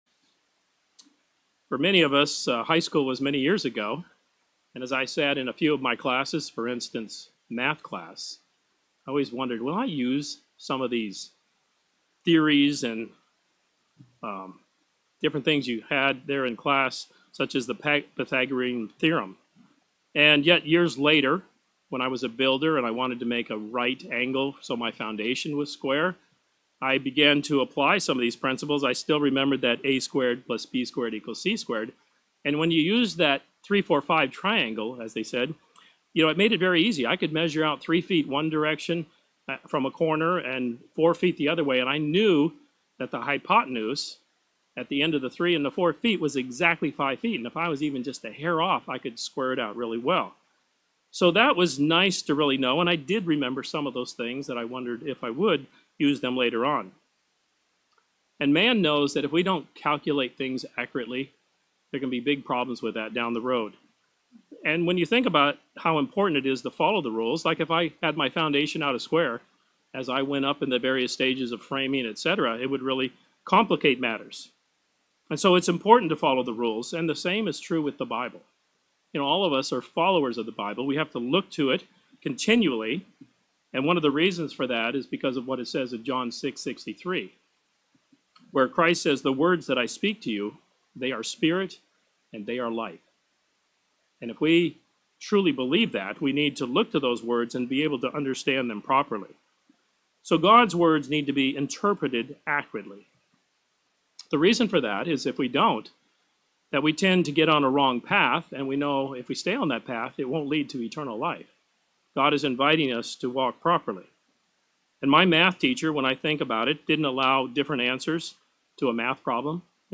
This sermon offers six points in how to improve our comprehension of God's word.